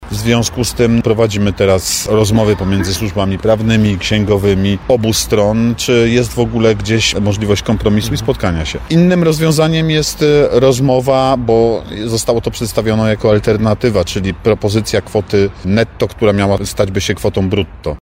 – Nasi księgowi nie chcą się zgodzić na to, by były to dotacje – mówi Artur Bochenek, zastępca prezydenta Nowego Sącza.